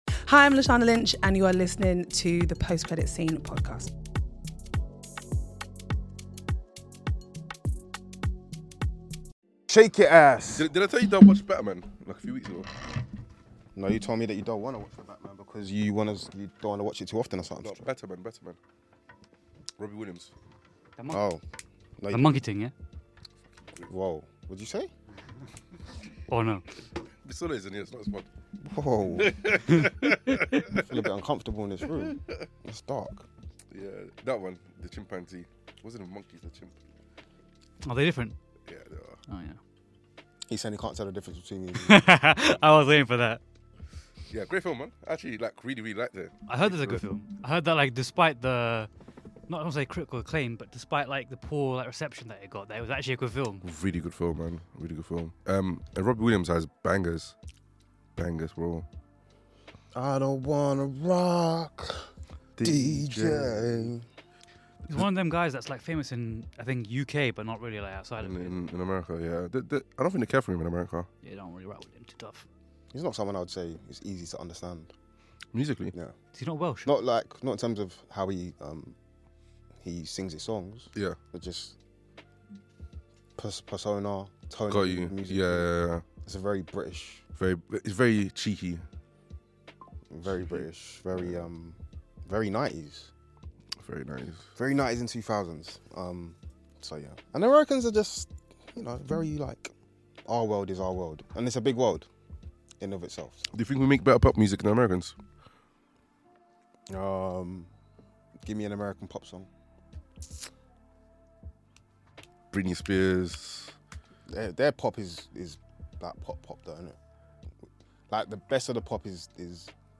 It’s the Season Finale of Post Credit Scene, and the full team is on deck to break down the films that defined cinema in 2025.